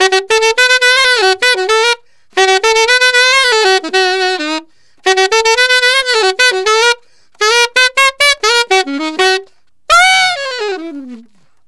Alto One Shot in Gb 01.wav